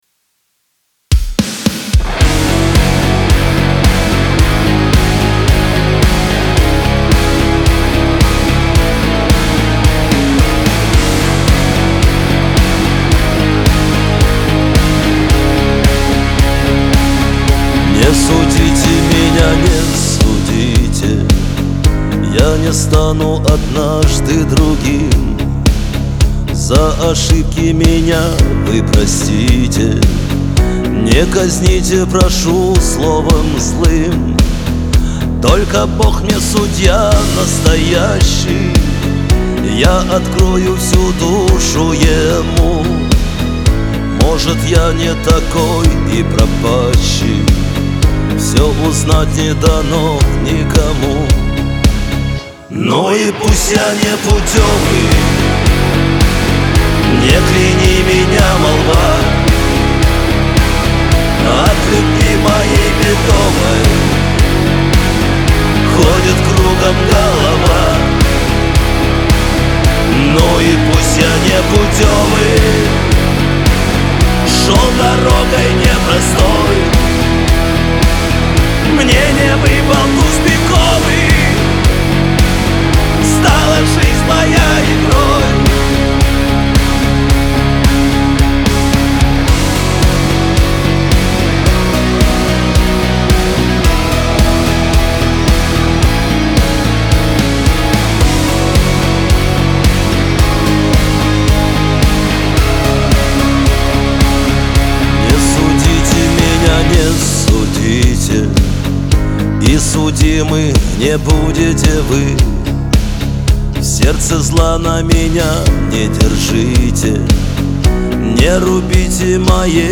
Лирика , эстрада
весёлая музыка